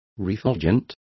Complete with pronunciation of the translation of refulgent.